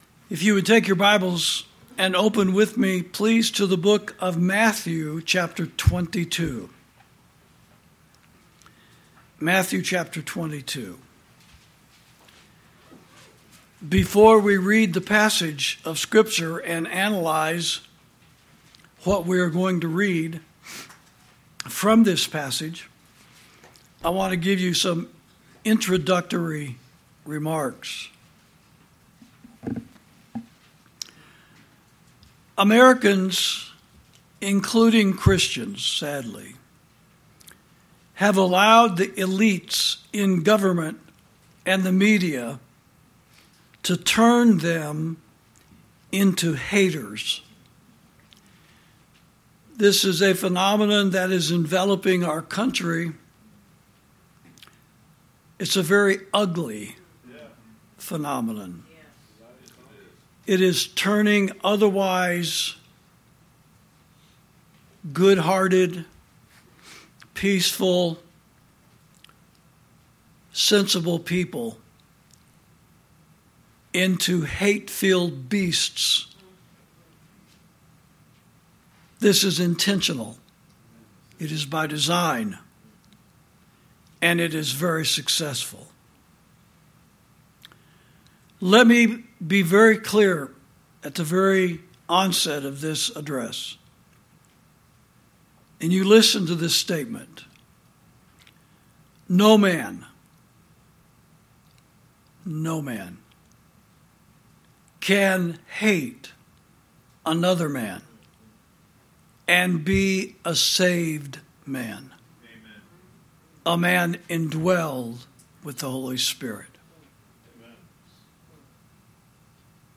Sermons > Christians Who Say They Love God And Laud Murder Are Liars